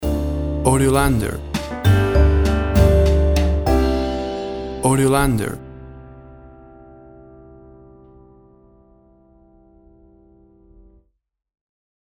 Tempo (BPM) 70